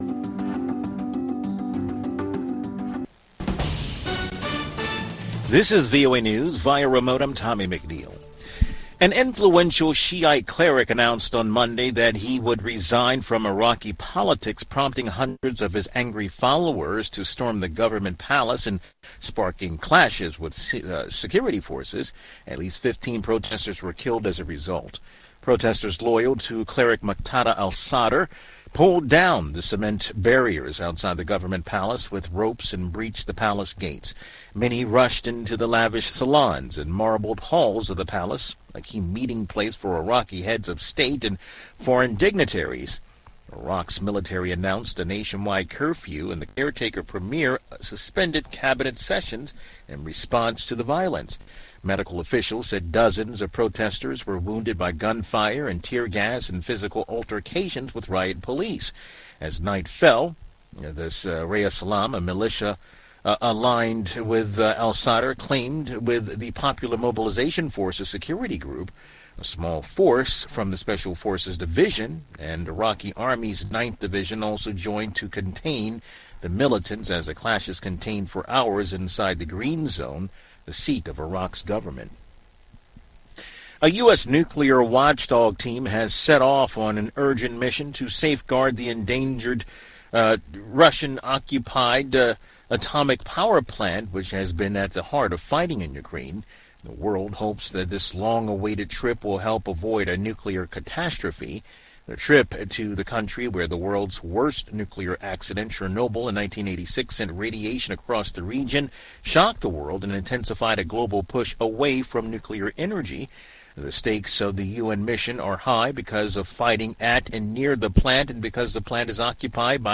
Voice of America: VOA Newscasts